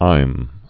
(īm)